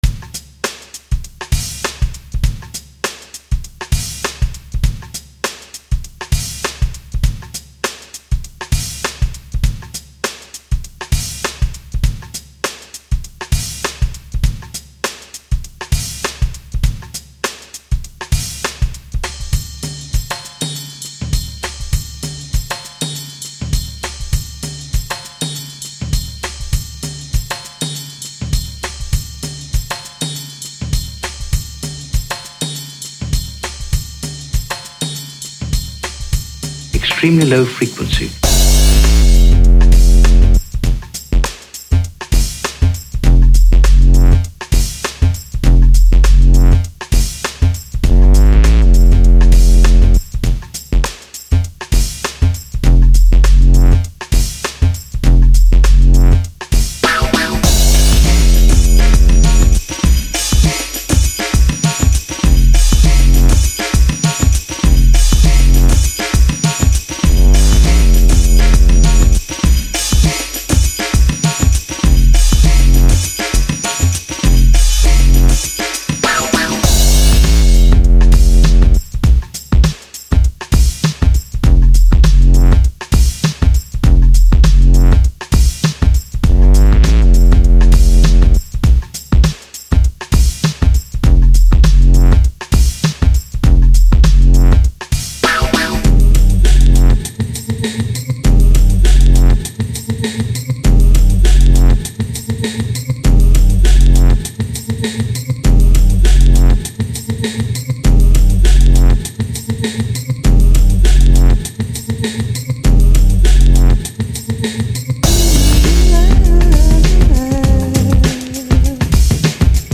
Trip Hop